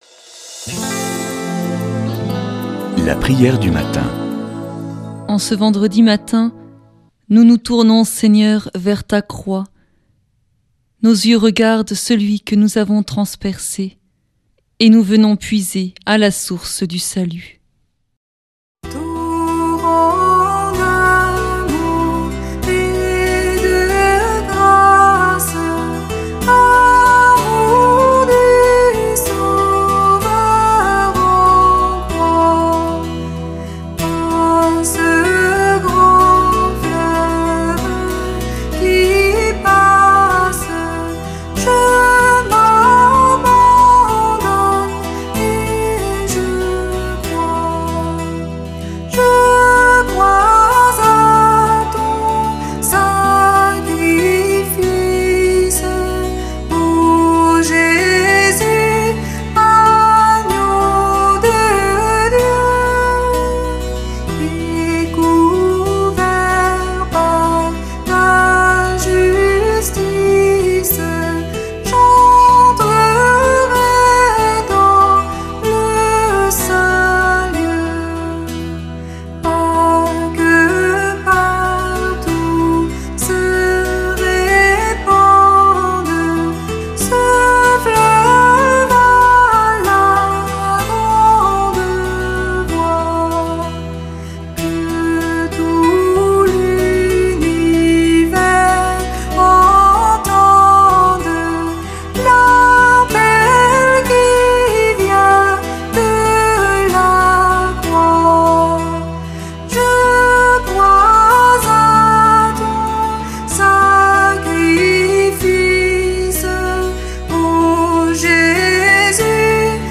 Prière du matin